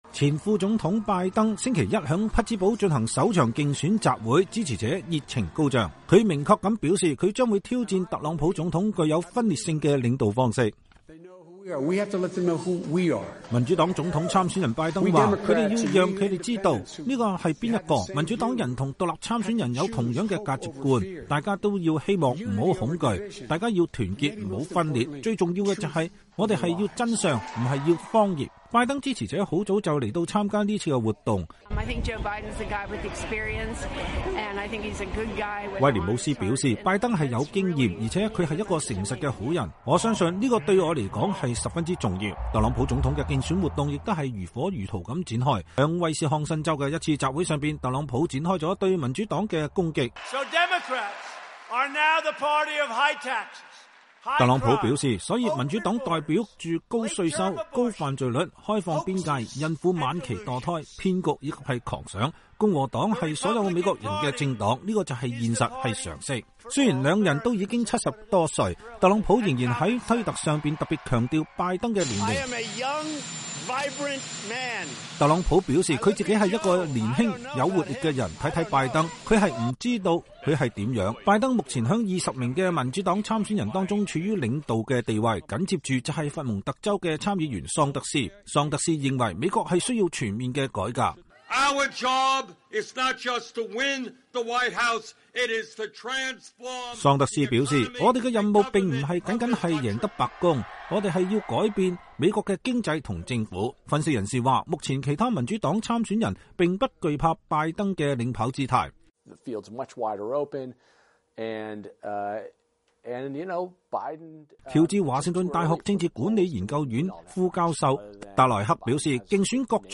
前副總統拜登週一在匹茲堡進行首場競選集會，支持者熱情高漲。
在威斯康辛州的一次集會上，特朗普展開了對民主黨的攻擊。